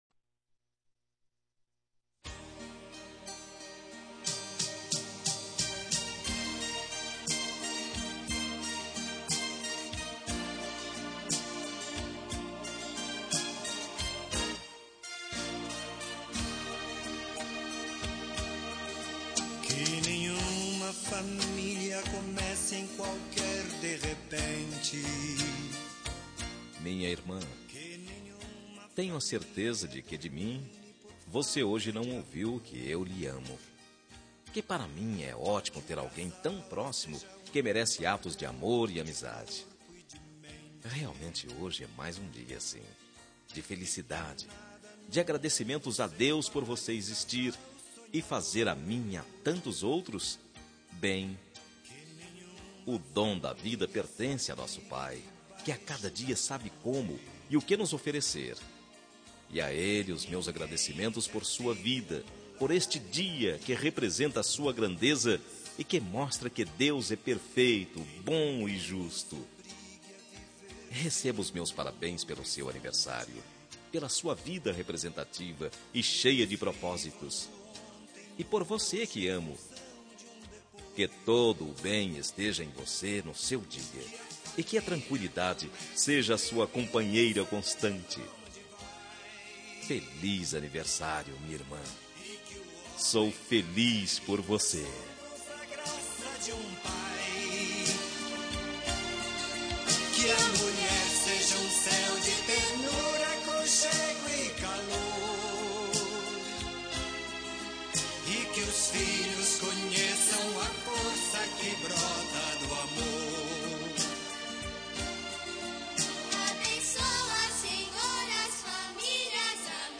Telemensagem de Aniversário de Irmã – Voz Masculina – Cód: 1683 – Religiosa